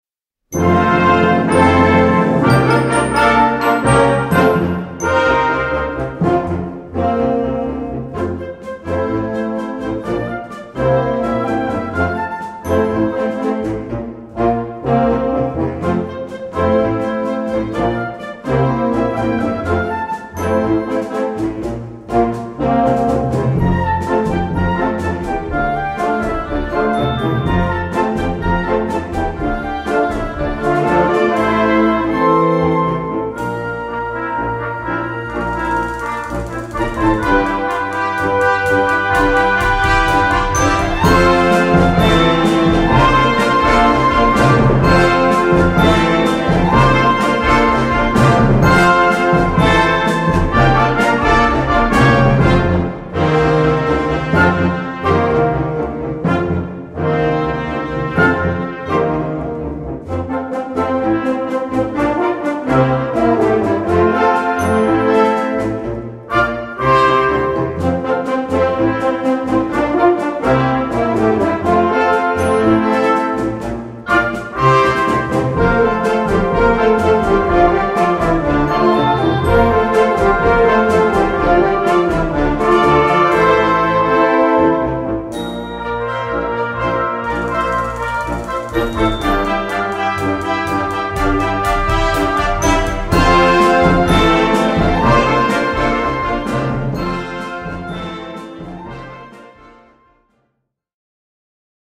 Recueil pour Orchestre - Orchestre Juniors